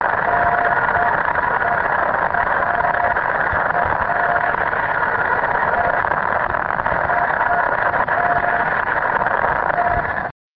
Having a lot of empty ground (and time and energy to put up the the antenna correctly!) resulted in this beacon being audible on its 177.26 KHz frequency.